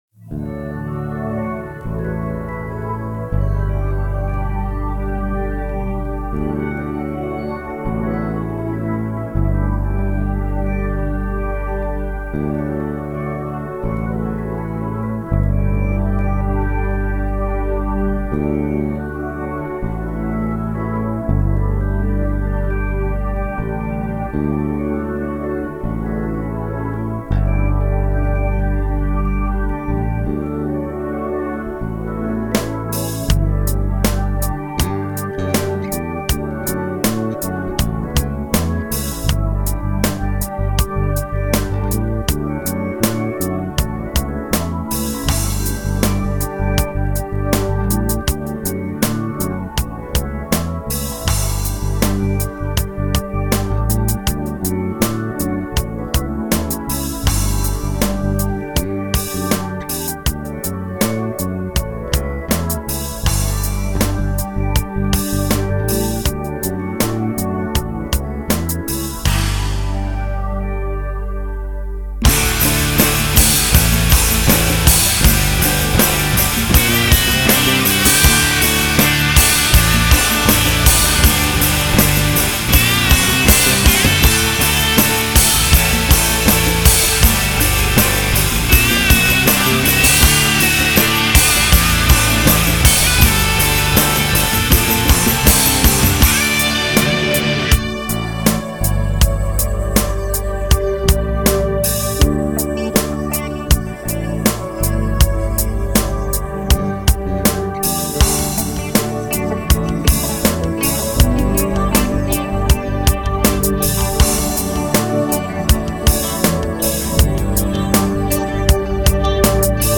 [Instrumental 2007, nie fertig gestellt.